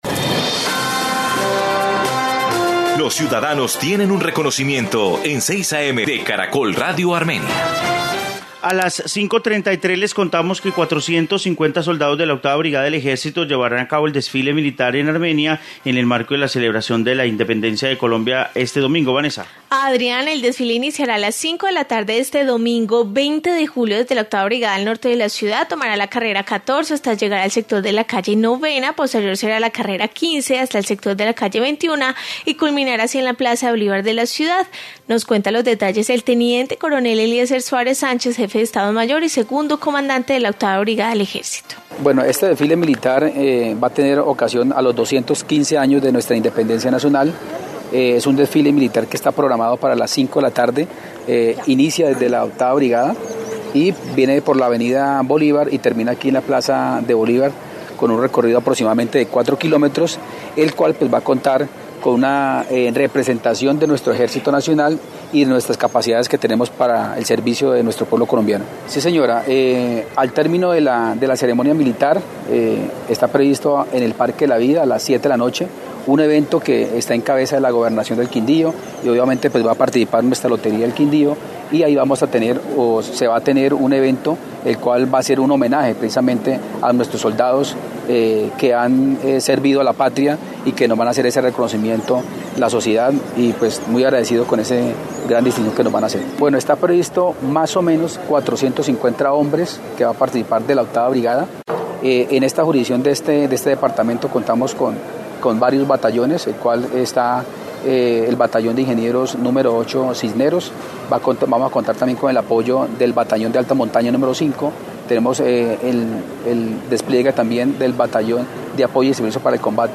Informe desfile militar en Armenia